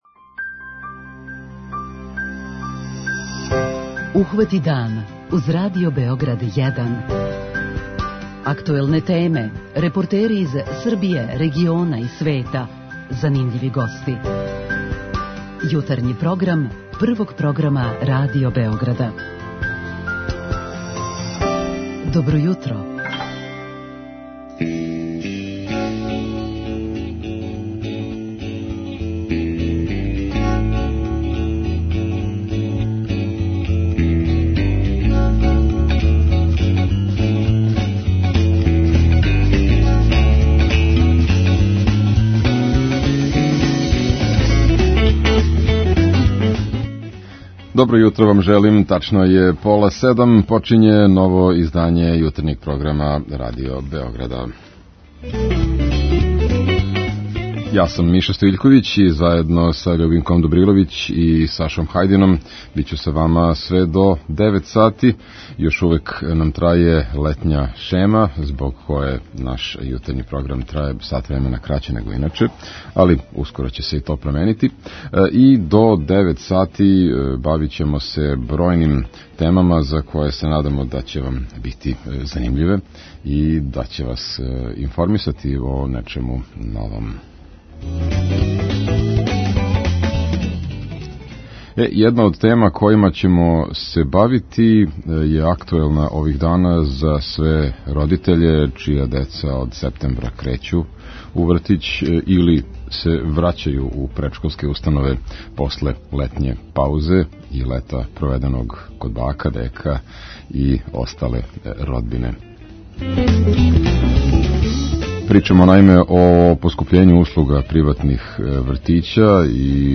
Пробудите се уз Радио Београд 1! преузми : 26.98 MB Ухвати дан Autor: Група аутора Јутарњи програм Радио Београда 1!